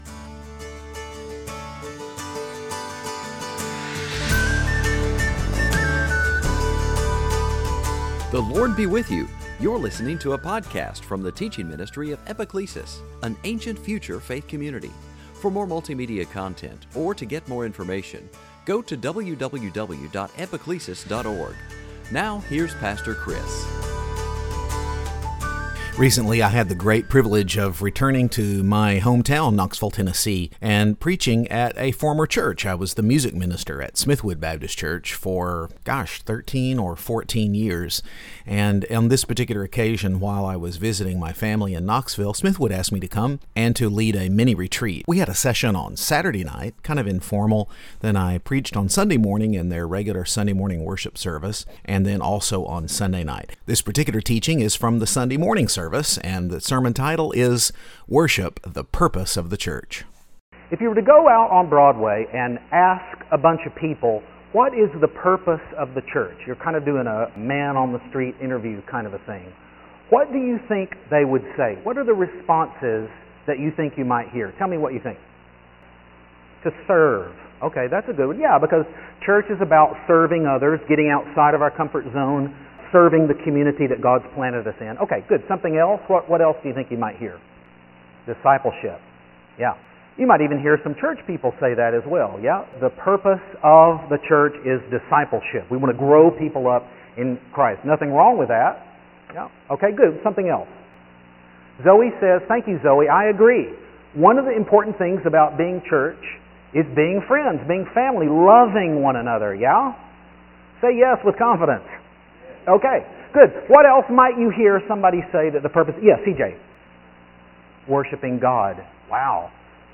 Here's the teaching for the Sunday morning worship service.